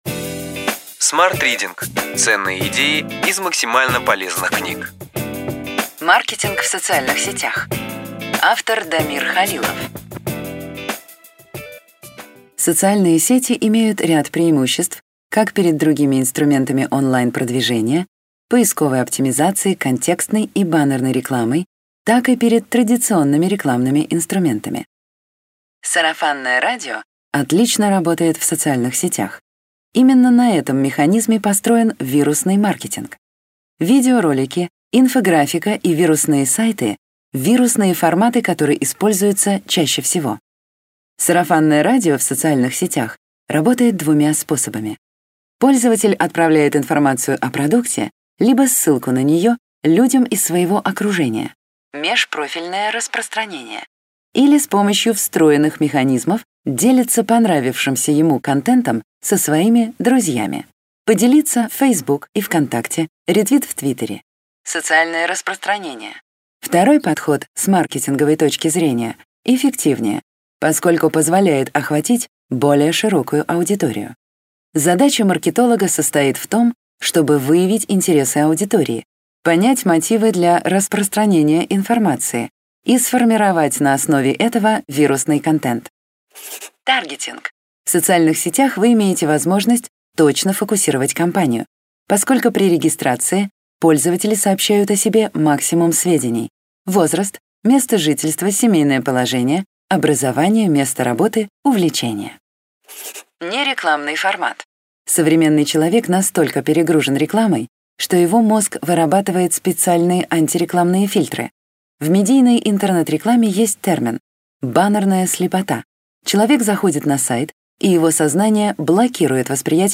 Аудиокнига Ключевые идеи книги: Маркетинг в социальных сетях.